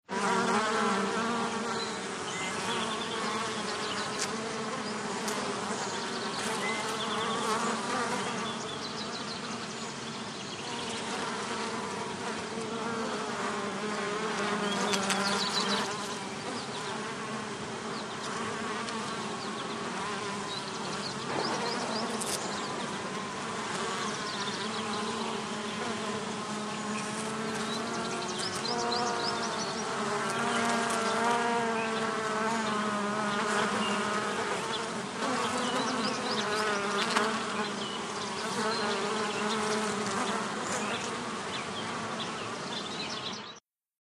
BeesManySummerDay PE660701
ANIMAL BEES: EXT: Many on a summer day, some close up bys, low level constant wind background, distant bird calls.